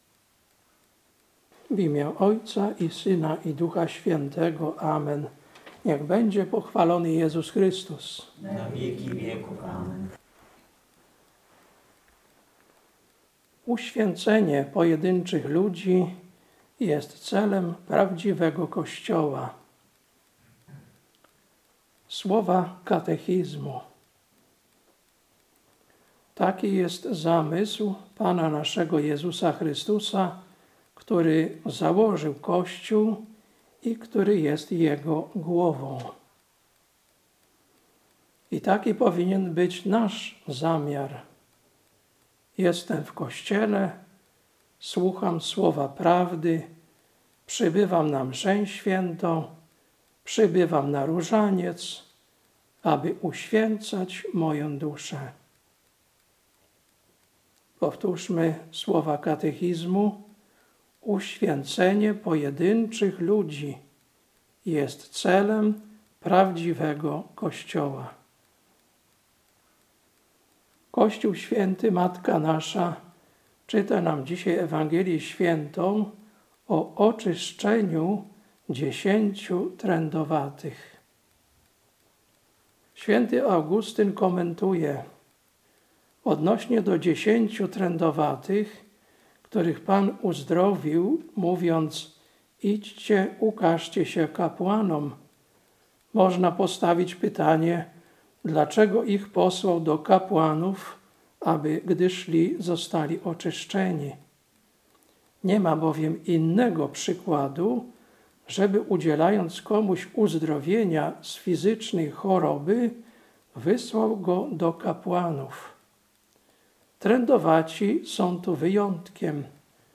Kazanie na XIII Niedzielę po Zesłaniu Ducha Świętego, 7.09.2025 Ewangelia: Łk 17, 11-19